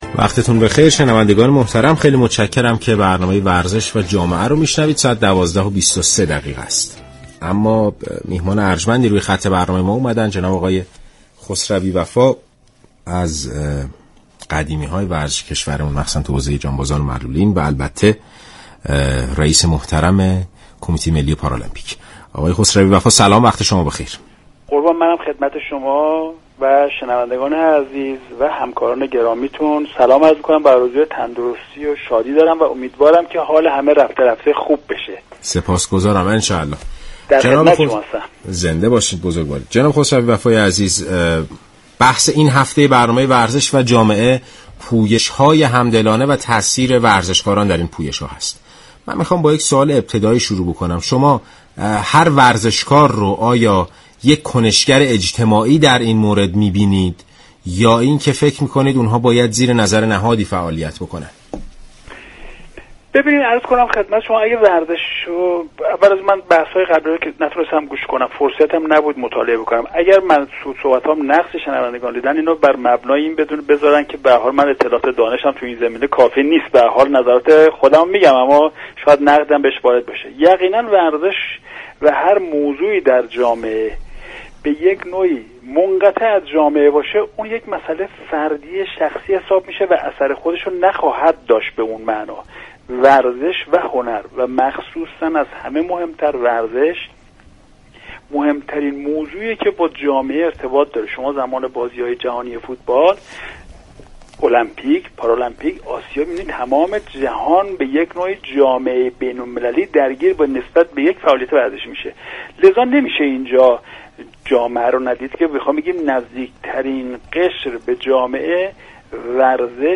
شما مخاطب محترم می توانید از طریق فایل صوتی پیوست شنونده ادامه صحبت های رئیس كمیته ملی پارالمپیك در برنامه ورزش و جامعه رادیو ورزش باشید.